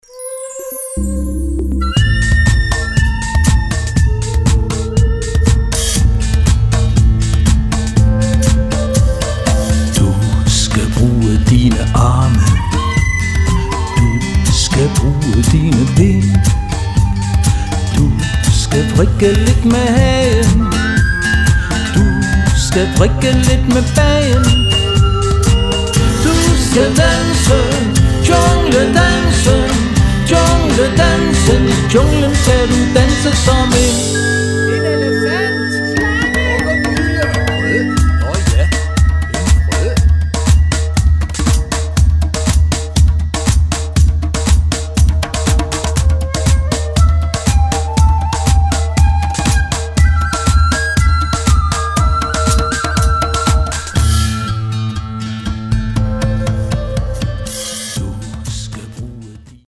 • Originale børnesange
Percussion
Vokal
Orangotangofesten er en børnekoncert med rytmer, sange, humor og historier.
Ved hjælp af guitar, midi og percussion